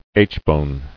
[aitch·bone]